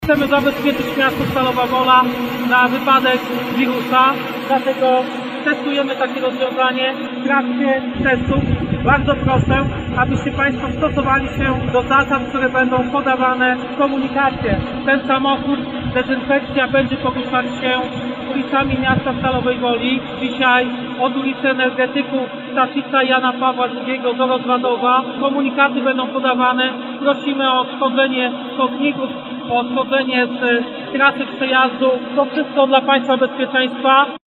Z samochodu wyposażonego w armatkę do rozpylania na ulicach i chodnikach dziś i jutro rozpylany będzie środek odkażający. O czym poinformował prezydent Stalowej Woli Lucjusz Nadbereżny.